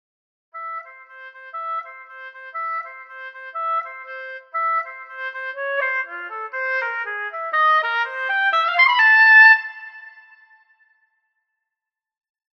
About the oboe
Played with Oboe 2
I played the first note with a single note (portato-medium) because the legato-samples often don't start with enough attack with their starting note.
Then I changed to the legato-articulation with the 2nd note, 3 and 4 I played with repetitions.